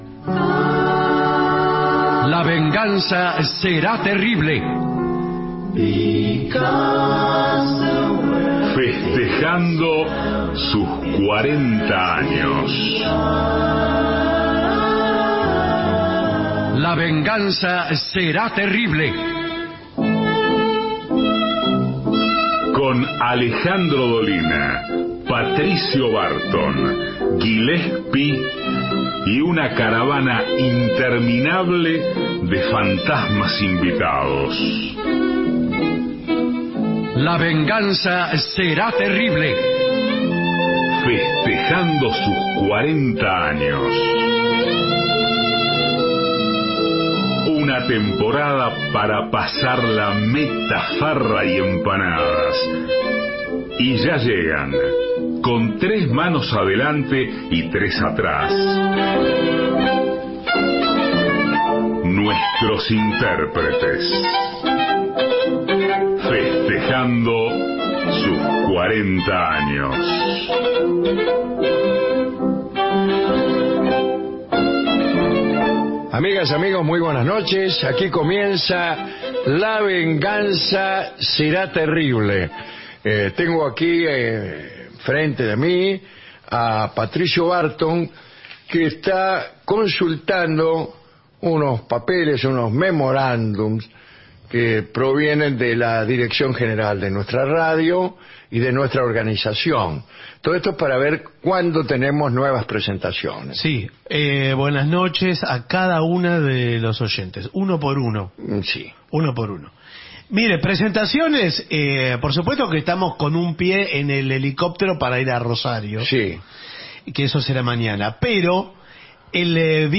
Estudios AM 750